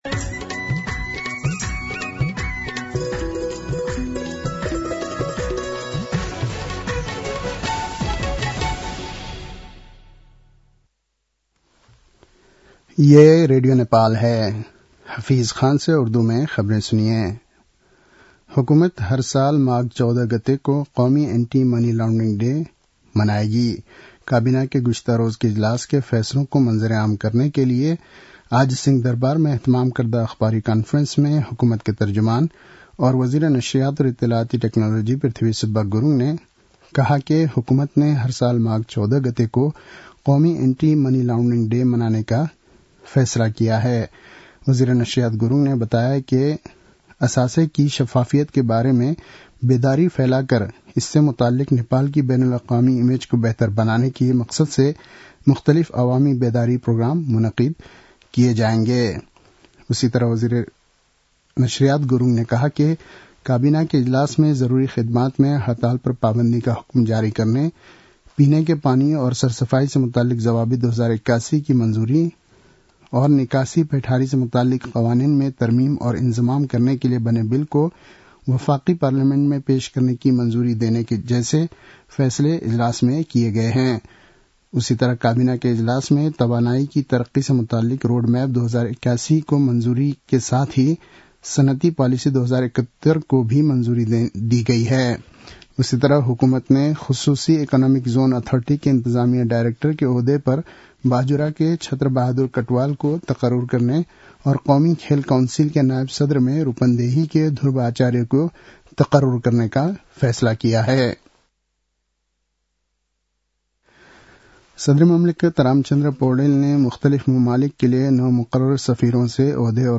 उर्दु भाषामा समाचार : १८ पुष , २०८१